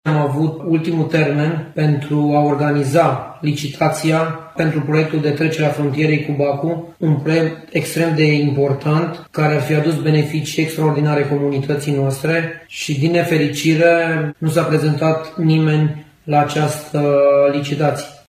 Este vorba de un proiect în valoare de 2,3 milioane de euro, derulat prin Programul IPA de Cooperare Tranfrontalieră România-Republica Serbia. Pierderea acestui proiect va avea consecinţe pe termen mediu şi lung, spune primarul Torma: